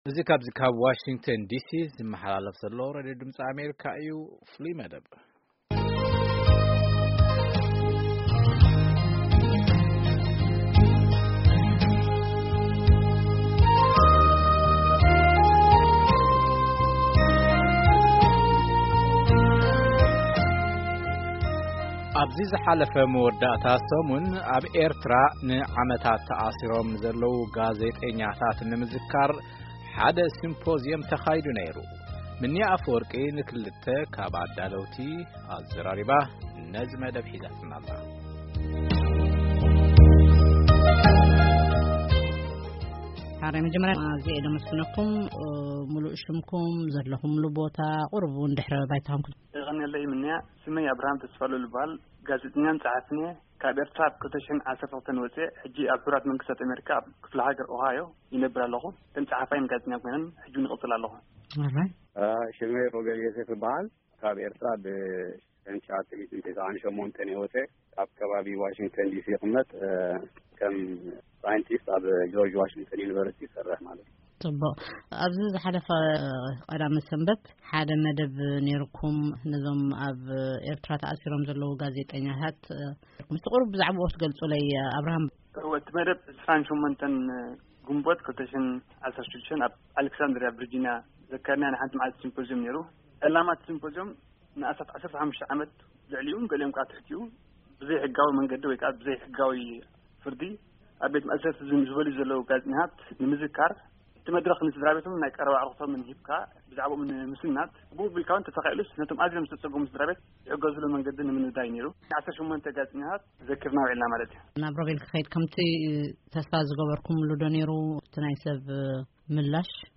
ቃለ-መጠይቕ ምስ ኣዳለውቲ ሲምፖዝዮም ዝኽሪ ንኣብ ማእሰርቲ ዝርከቡ ኤርትራዊያን ጋዜጠኛታት